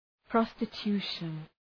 Προφορά
{,prɒstı’tu:ʃən}